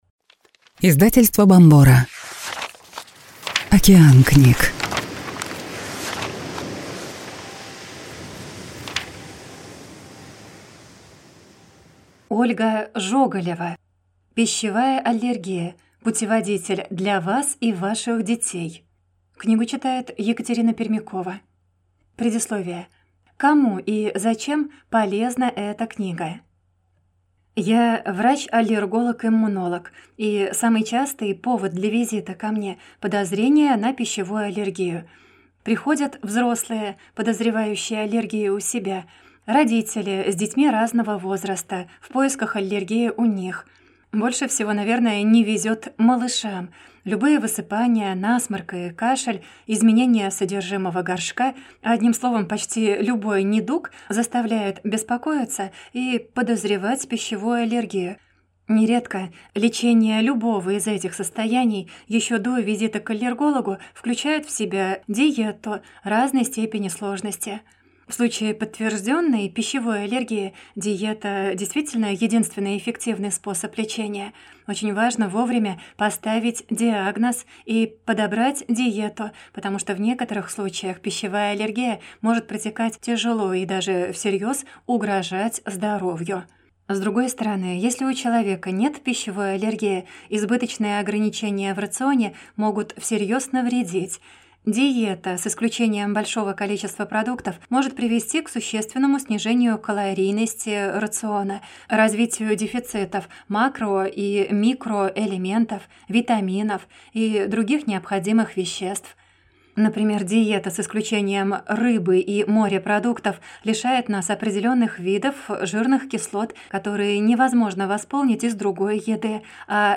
Аудиокнига Пищевая аллергия. Как с ней справиться?